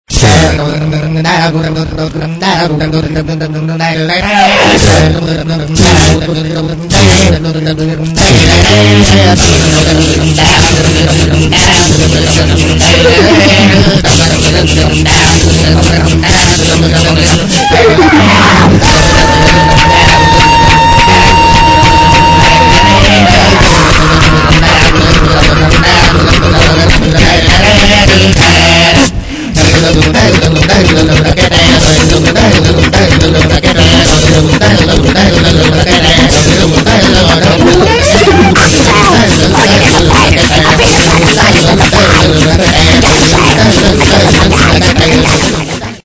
Неаvу mеtаl